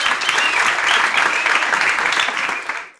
Polite Applause
A small audience giving polite, measured applause in an indoor auditorium setting
polite-applause.mp3